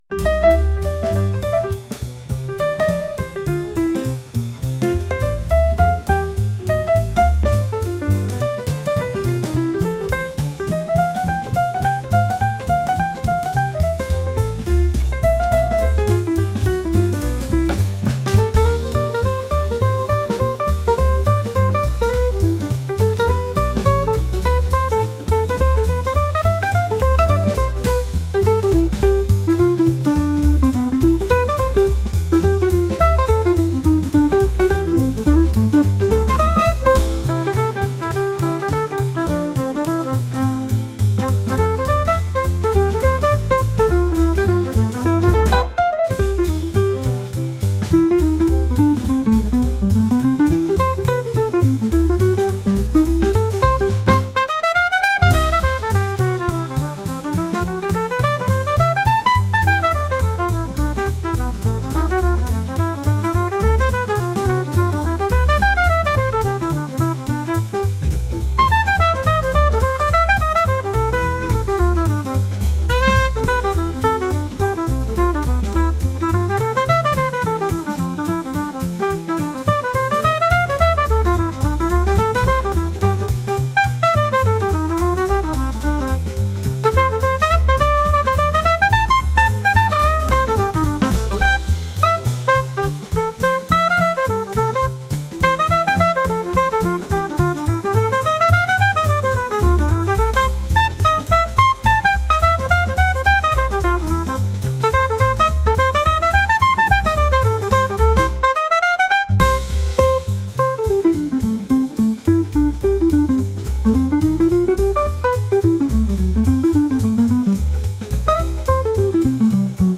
トランペット多めのクラシックジャズ曲です。